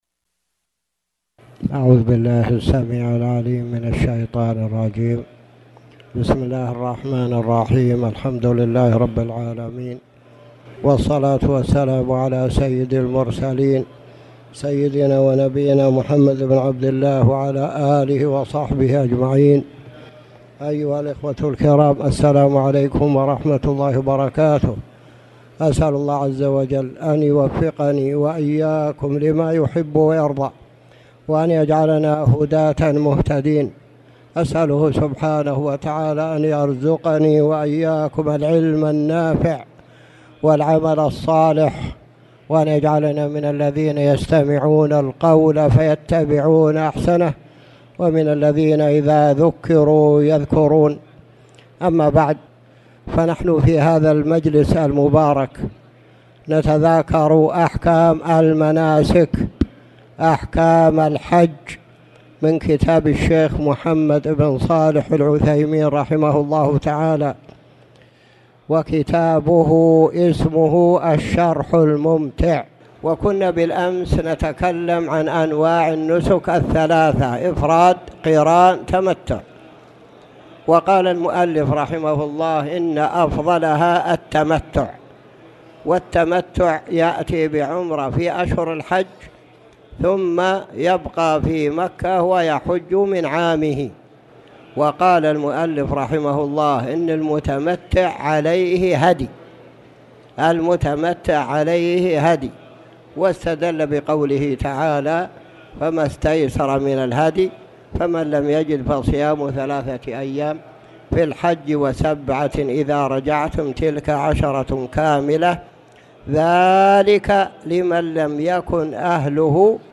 تاريخ النشر ١٥ ذو القعدة ١٤٣٨ هـ المكان: المسجد الحرام الشيخ